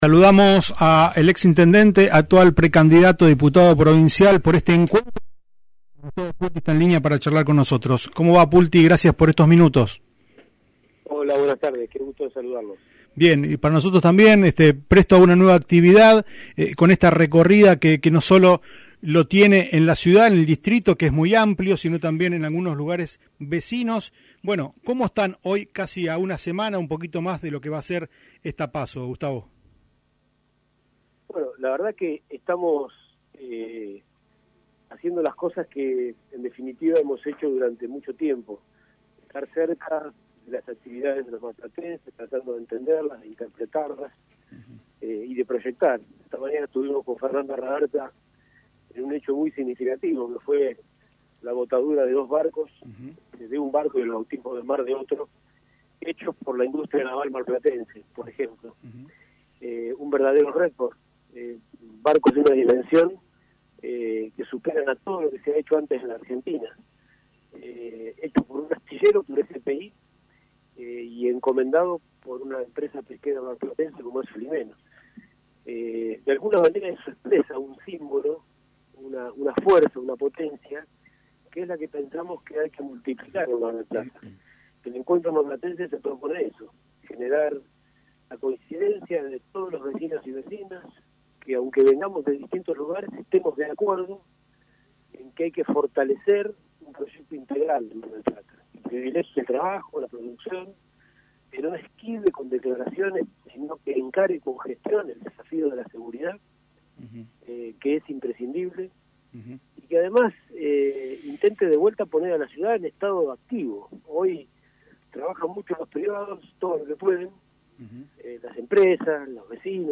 Por este motivo, el dos veces intendente de General Pueyrredon y actual precandidato a diputado provincial, Gustavo Pulti, pasó por los estudios de "UPM" de Radio Mitre y repasó en qué condiciones se encuentra la ciudad y cómo se encuentra desarrollando la campaña junto a Fernanda Raverta, con quien comparte espacio en Encuentro Marplatense.